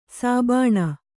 ♪ sābāṇa